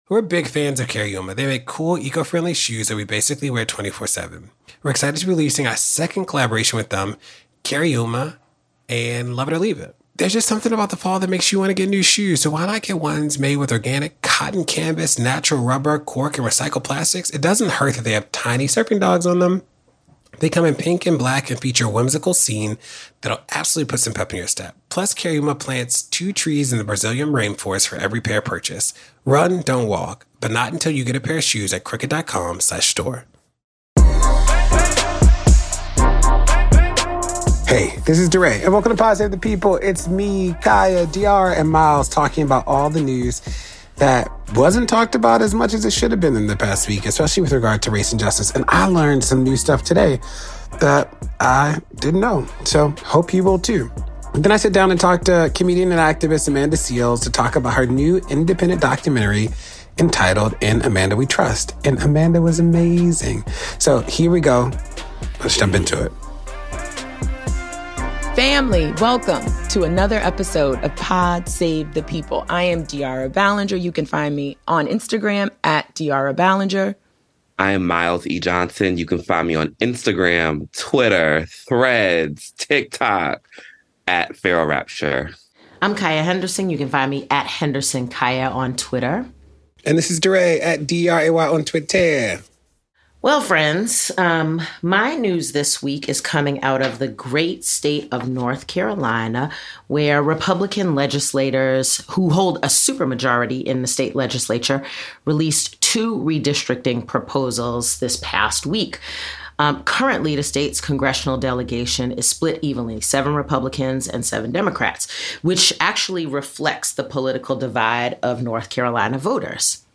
DeRay interviews Amanda Seales about her new political-comedy documentary 'In Amanda We Trust'.